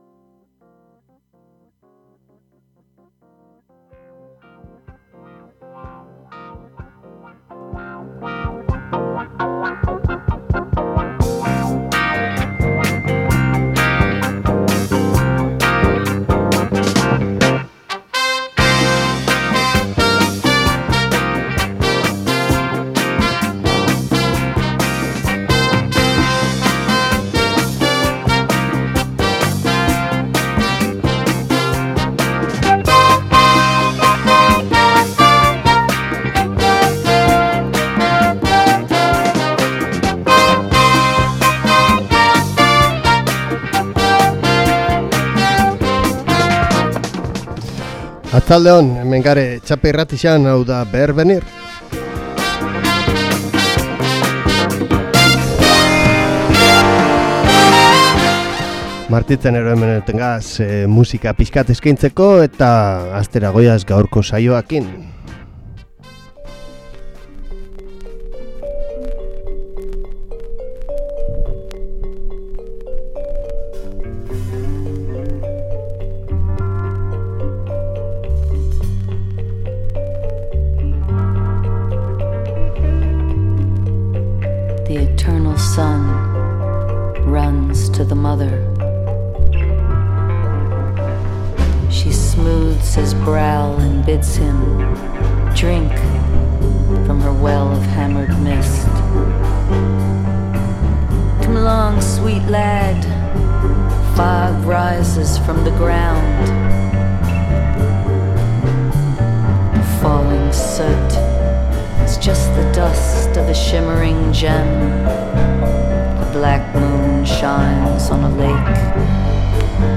BERBENIR- suabe hasieran ta zarata pixkat bukaeran.